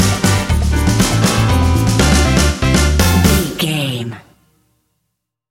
An exotic and colorful piece of Espanic and Latin music.
Aeolian/Minor
flamenco
maracas
percussion spanish guitar
latin guitar